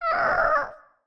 Minecraft Version Minecraft Version 25w18a Latest Release | Latest Snapshot 25w18a / assets / minecraft / sounds / mob / ghastling / ghastling1.ogg Compare With Compare With Latest Release | Latest Snapshot
ghastling1.ogg